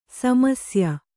♪ samasya